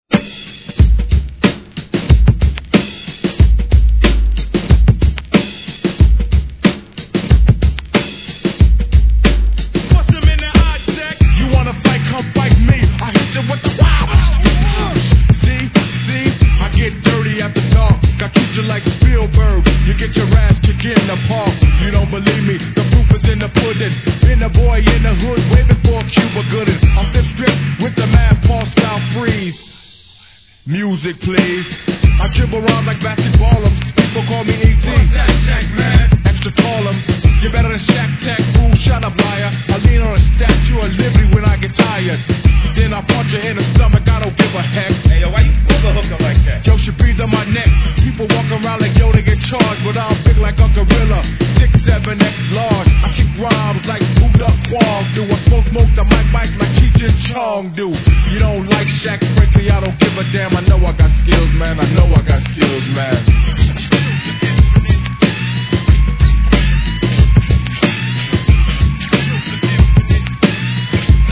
HIP HOP/R&B
WESTCOAST仕上げのLP VER.にタイトなBEATがCOOLなREMIXも◎